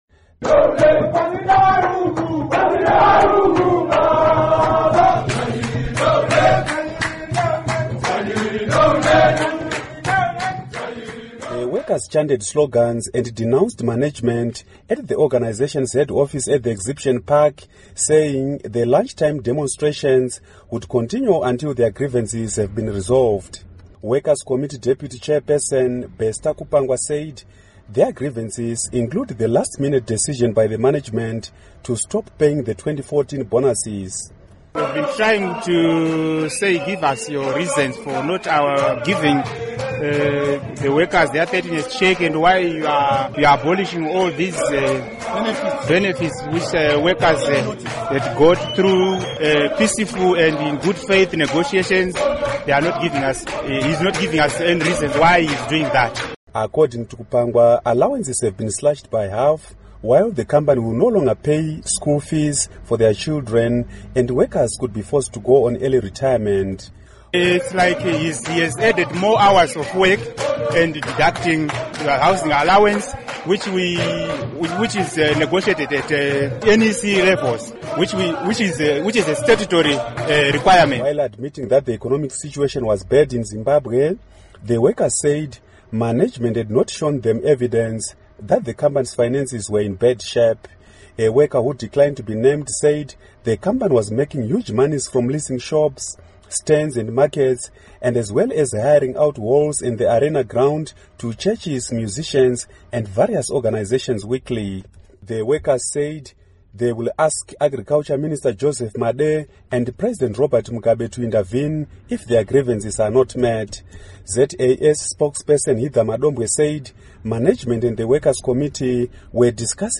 Report on Workers' Street Demonstrations